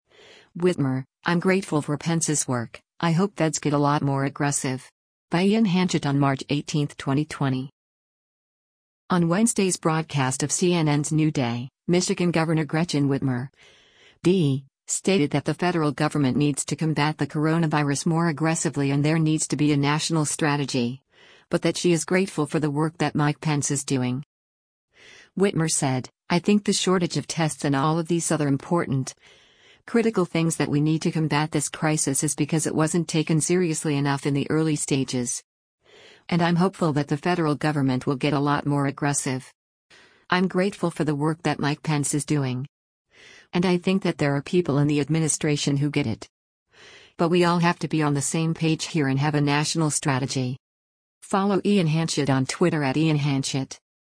On Wednesday’s broadcast of CNN’s “New Day,” Michigan Governor Gretchen Whitmer (D) stated that the federal government needs to combat the coronavirus more aggressively and there needs to be a national strategy, but that she is “grateful for the work that Mike Pence is doing.”